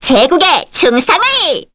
Rodina-Vox_Victory_kr.wav